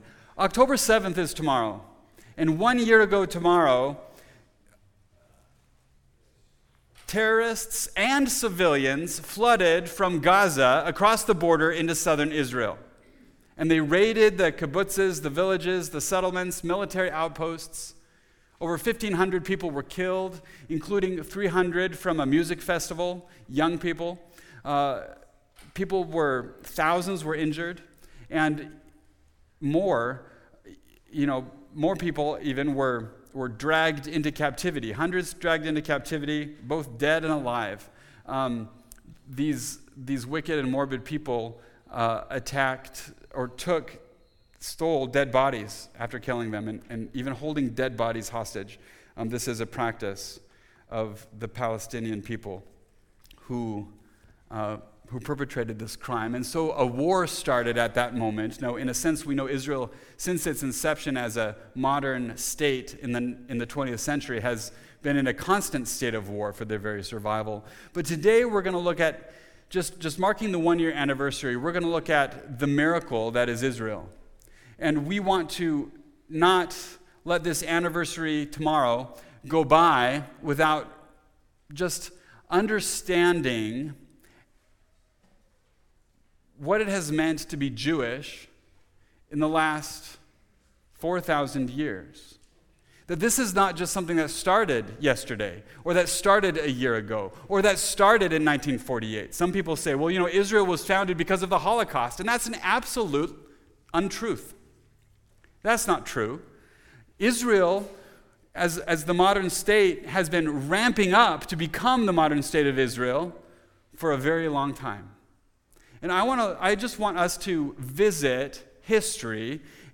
The notes for this sermon are a slide presentation contained in the “Save .PDF” file.